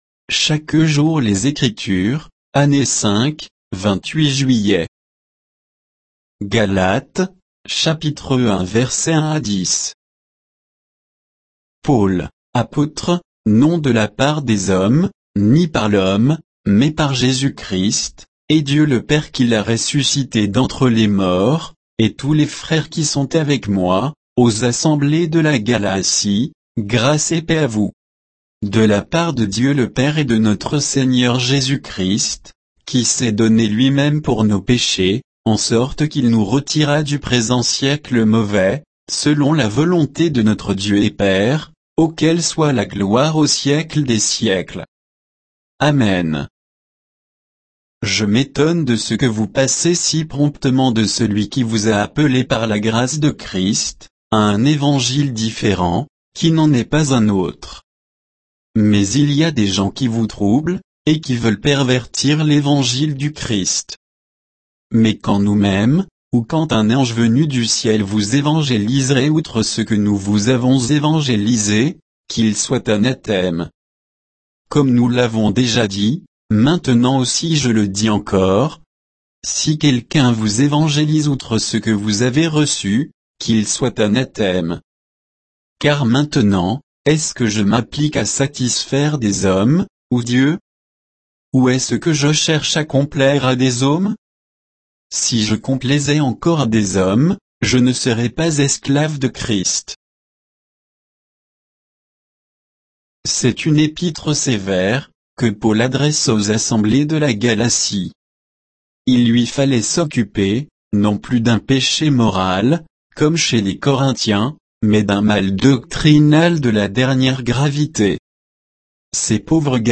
Méditation quoditienne de Chaque jour les Écritures sur Galates 1, 1 à 10